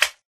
Sound / Minecraft / mob / magmacube / small2.ogg